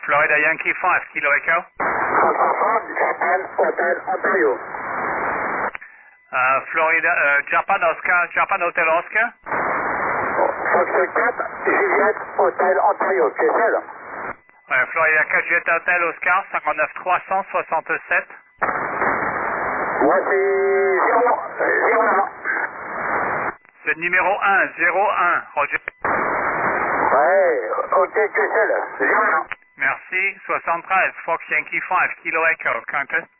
1. The saturation trap: By re-listening to your SSB passes, you will quickly identify if your compressor is set too high or if there is RF feedback. A distorted signal fatigues the remote operator’s ears and reduces your chances of being picked up quickly.
FY5KE_audioclips_audio_with_problem.mp3